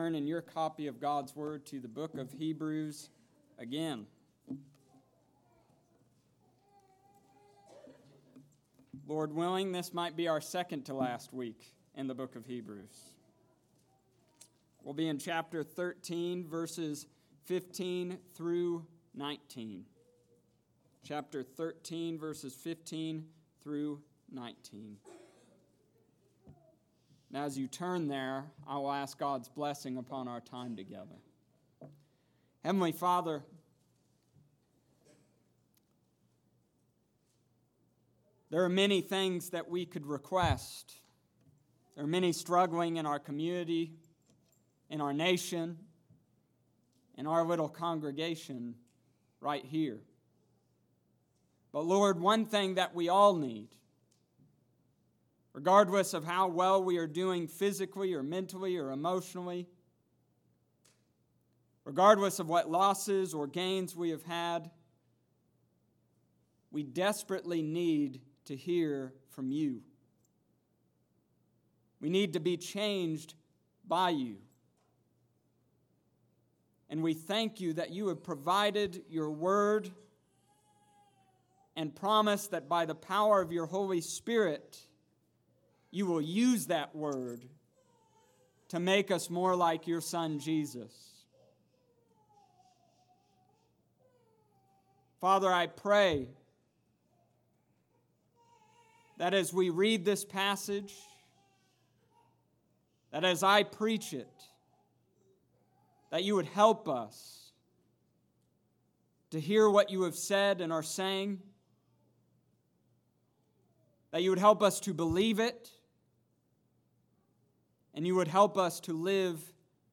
Summary of Sermon: This week, we learned that we need to keep the faith.